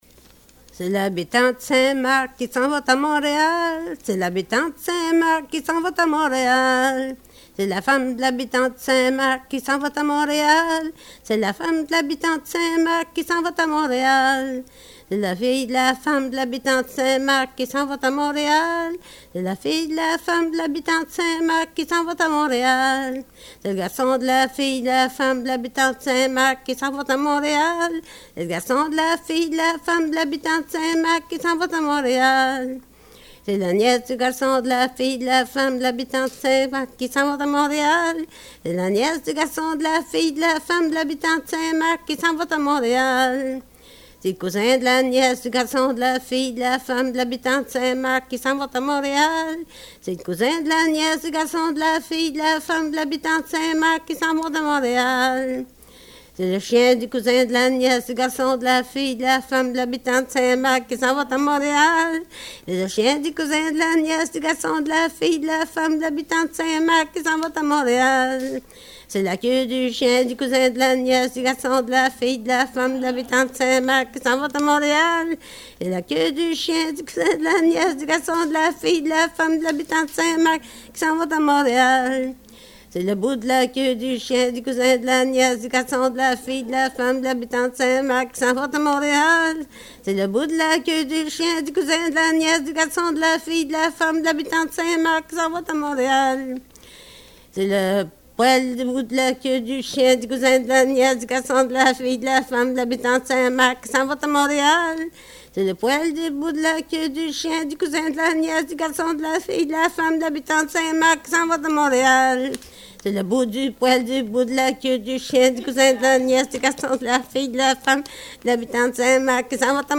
Folk Songs, French--Québec (Province)
Here we have what appears to be a song which originates in Quebec. It is a “cumulative song”: a song with a simple verse structure modified by progressive addition so that each verse is one line longer than the verse before.